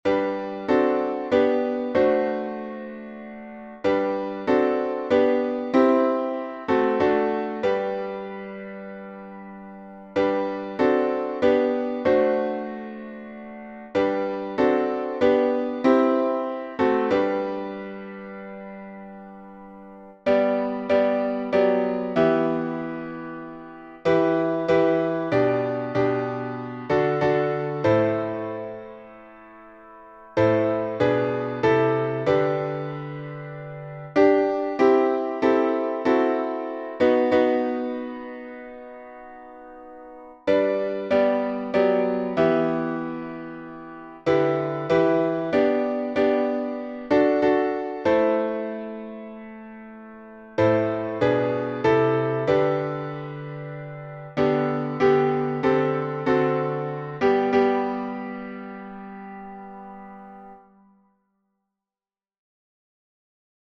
#5042: Be Still My Soul — G Major with 5 stanzas | Mobile Hymns